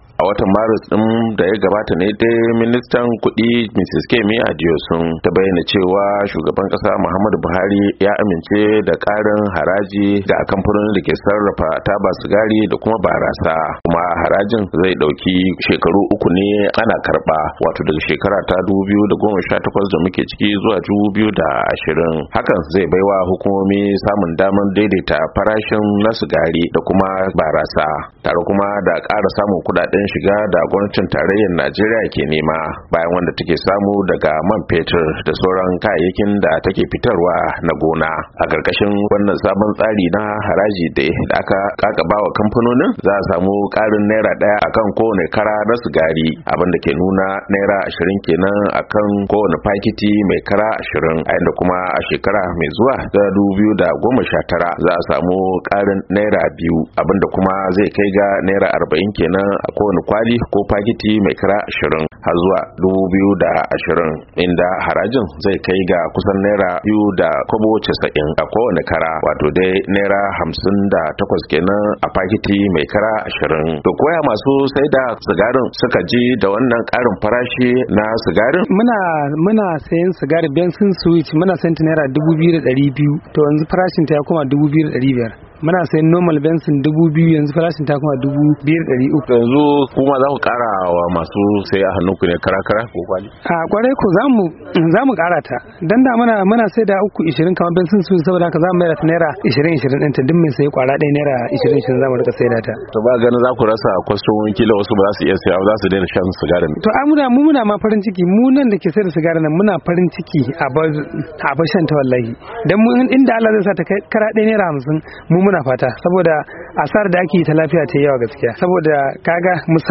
Wani mai sayar da sigari ya ce lallai farashin tabar sigari zai karu kuma duk da cewa mai yiwuwa jama'a za su rage sayanta, wannan abin farin ciki ne.